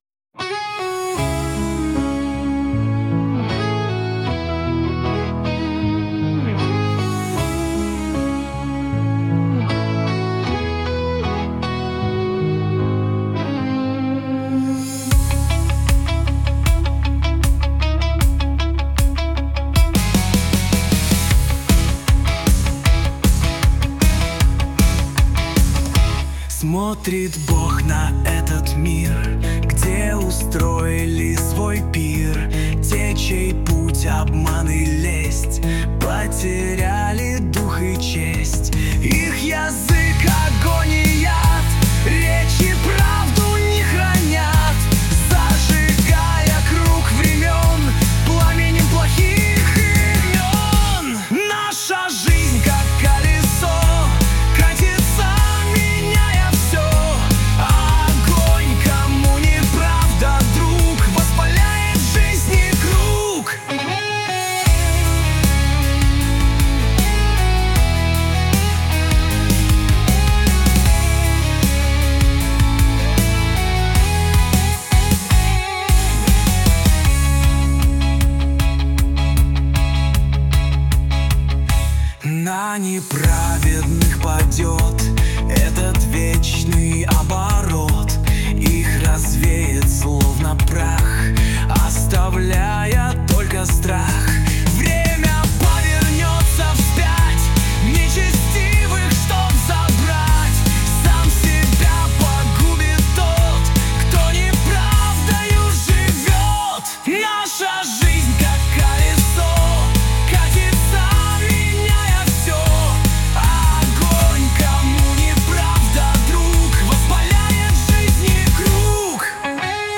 песня ai
226 просмотров 1252 прослушивания 102 скачивания BPM: 78